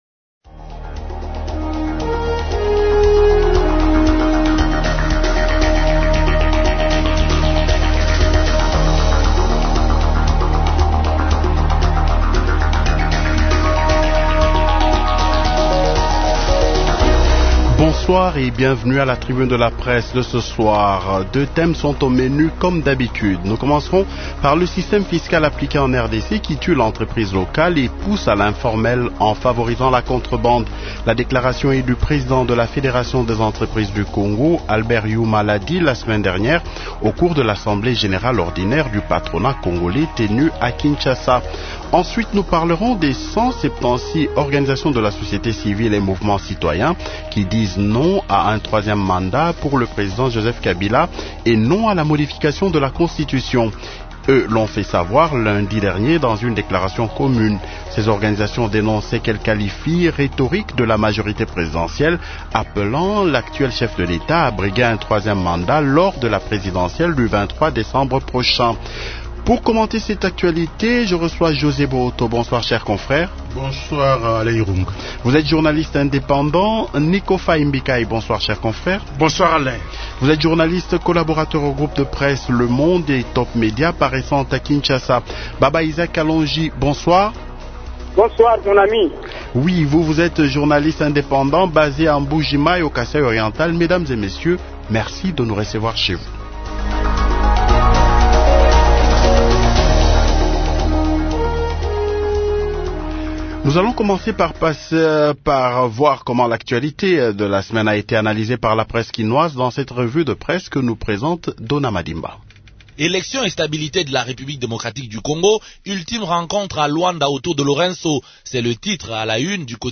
journaliste indépendant.